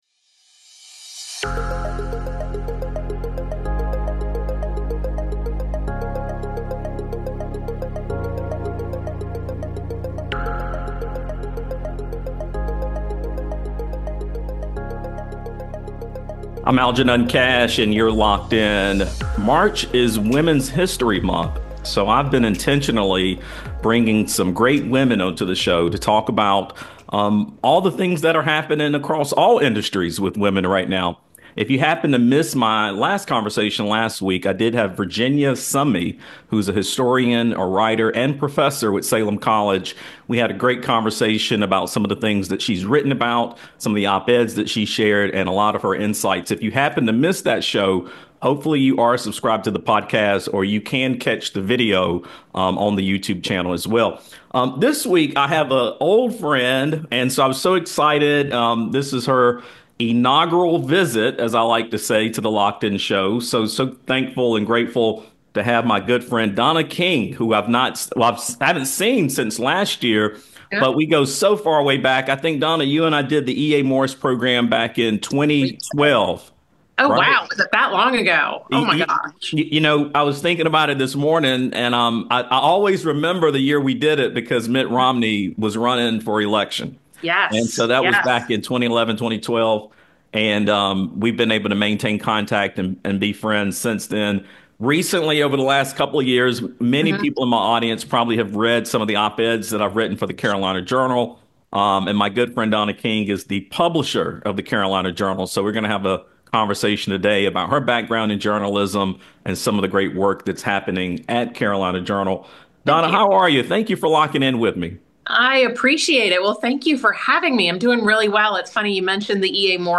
Women in Journalism: A conversation